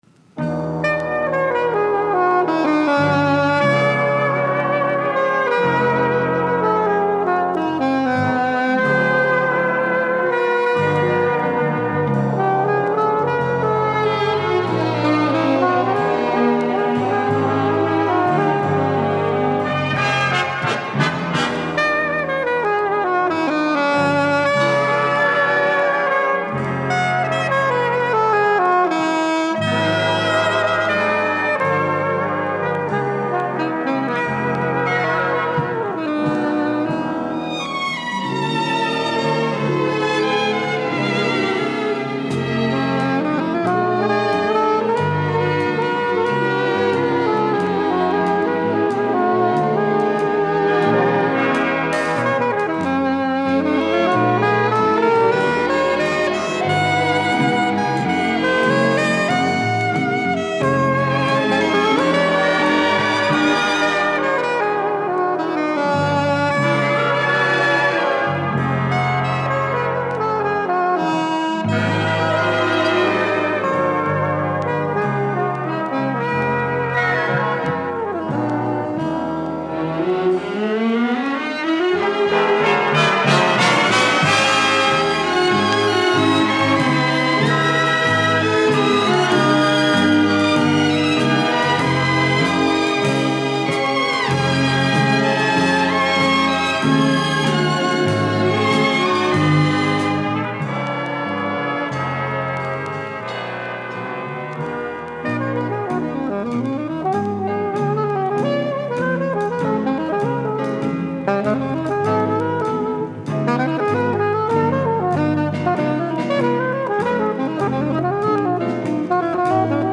ボッサジャズ
中盤のスウィンギーな転調も感動的な
優美なボサノヴァのリズムに乗った